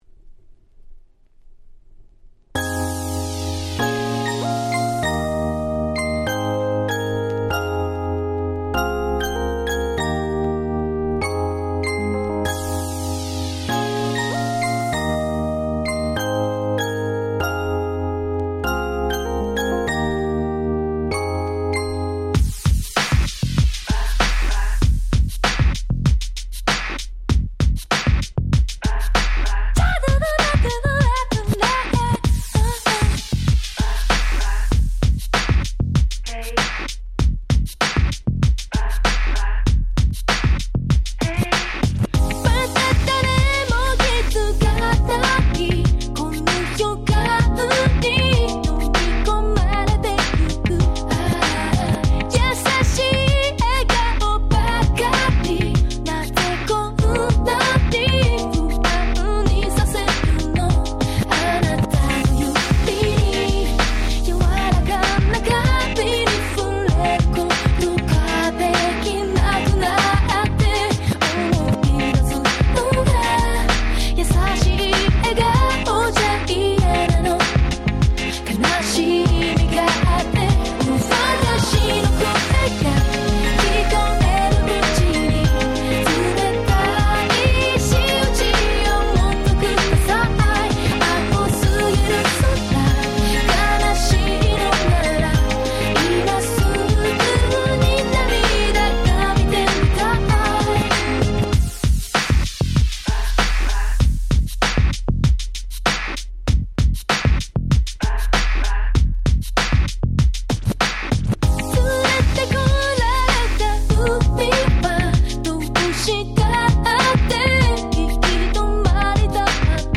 01' Nice Japanese R&B !!